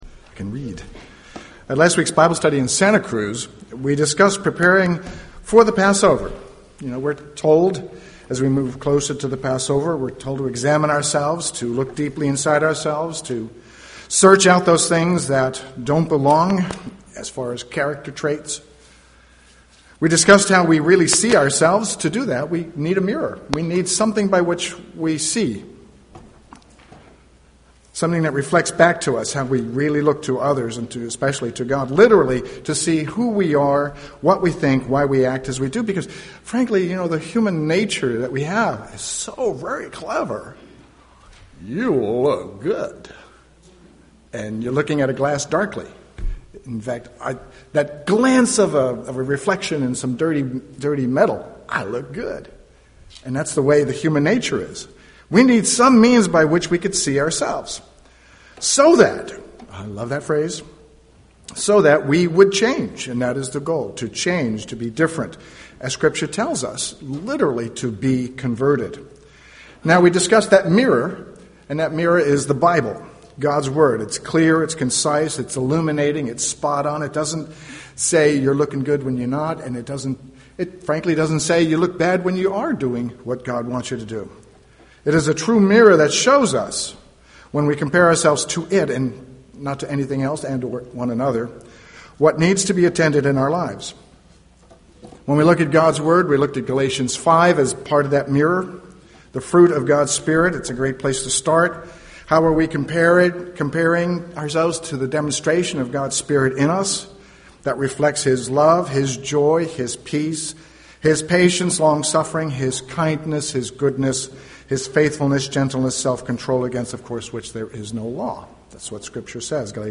Given in San Jose, CA
UCG Sermon Studying the bible?